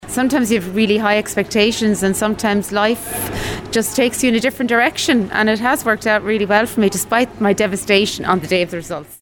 WLR went out to speak to people about their experiences and whether their results had any impact on their lives.
A woman who had hoped for better results didn’t get the course that she wanted and decided to go to college anyway. She was pleasantly surprised with how her life turned out: